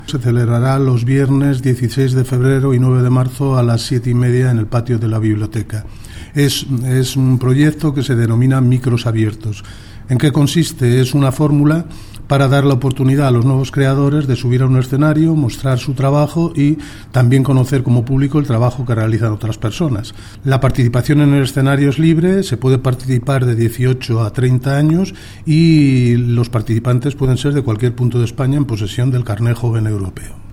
El director provincial de Educación, Cultura y Deportes en Guadalajara, Faustino Lozano, habla del certamen de micros abiertos.